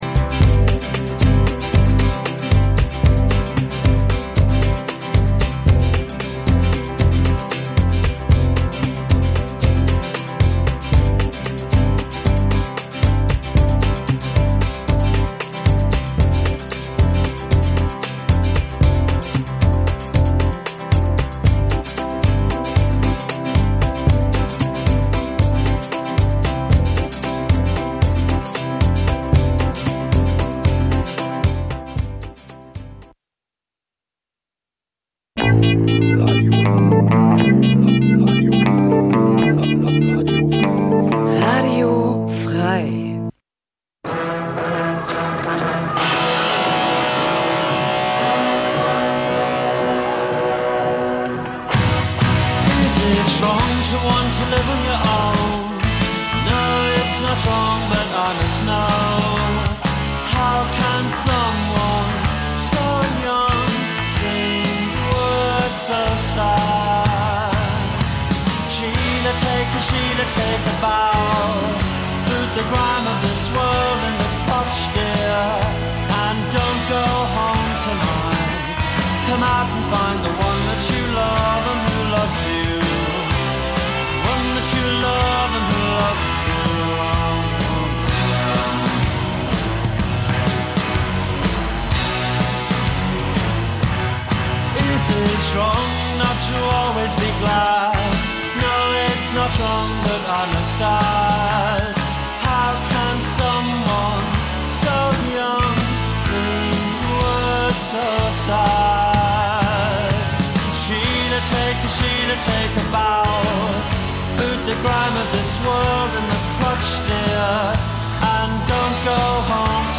Musiksendung